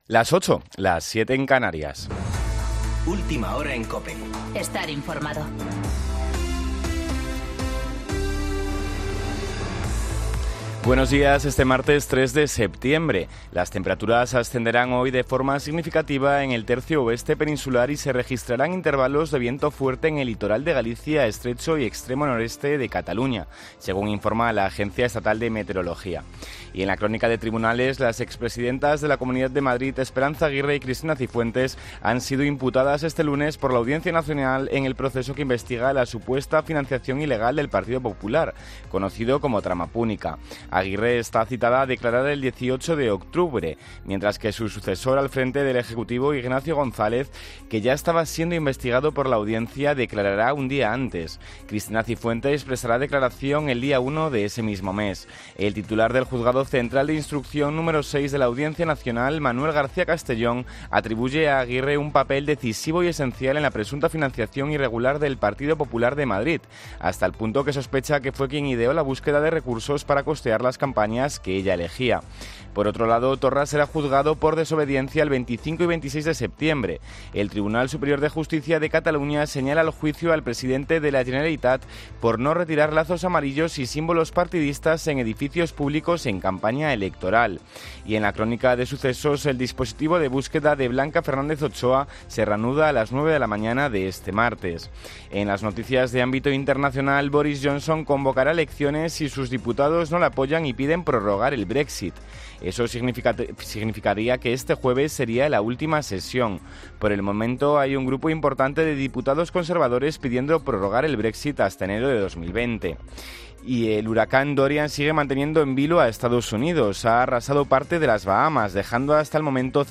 Boletín de noticias COPE del 3 de septiembre de 2019 a las 08.00 horas